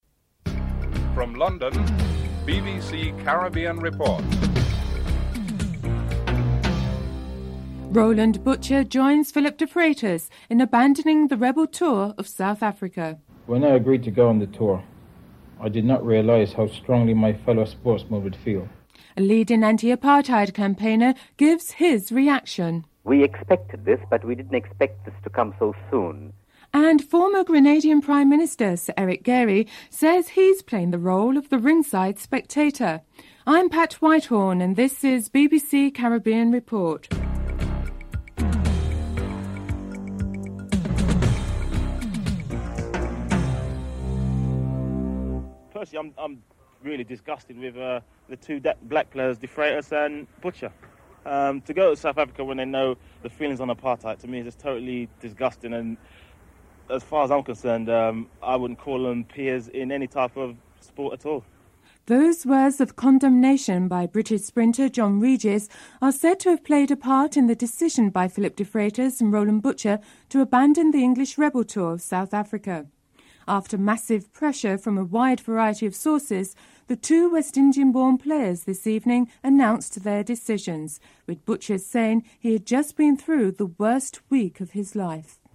1. Headlines (00:46-01:18)
3. Financial news (06:40-07:33)